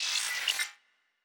Data Calculating 4_3.wav